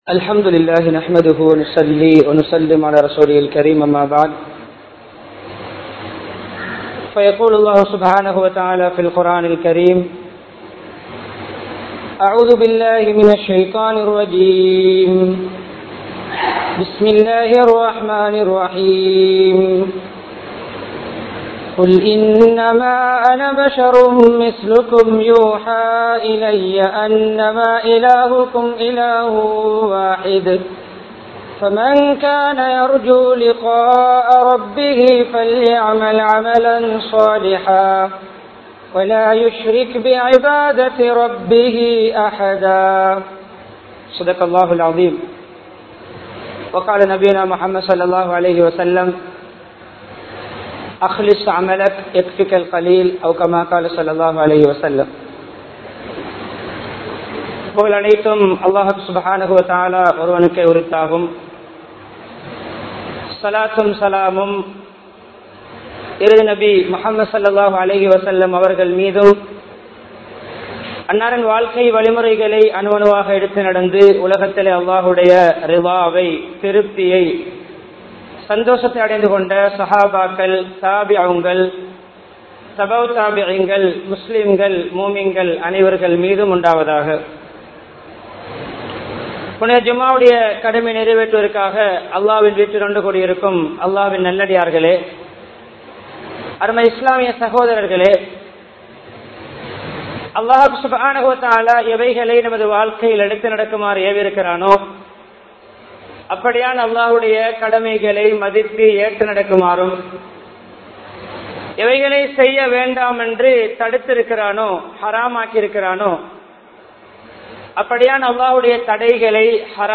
Ihlas (இஹ்லாஸ்) | Audio Bayans | All Ceylon Muslim Youth Community | Addalaichenai